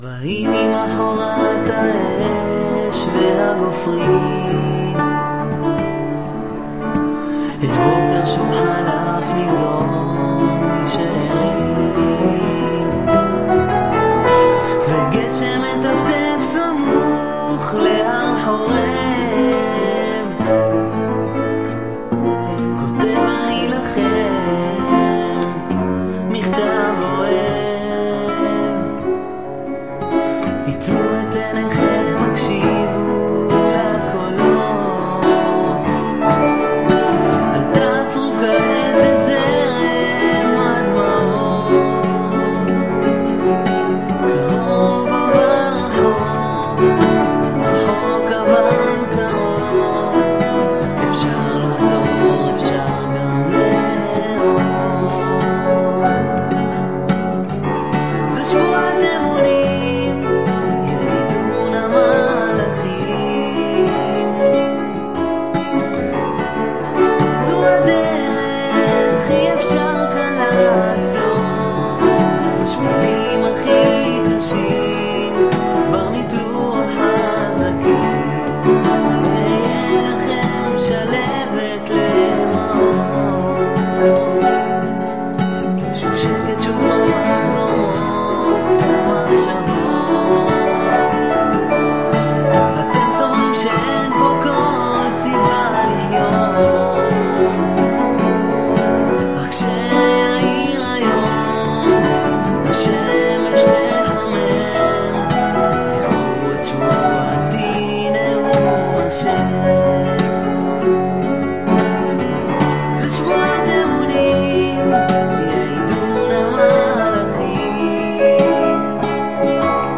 שיתוף פעולה של כמה יוצרים באתר כסקיצה לניסיון לפרויקט ישן של הוצאת דיסק משותף של יוצרי שירה ומוזיקה..
פסנתר: אני
אני יודע שלפעמים הפסנתר לא הכי מתואם עם השירה והגיטרה, זה בגלל שאלו שתי הקלטות נפרדות..
לחן באמת מקסים שמביא גוון מזרחי וזה מוסיף המון.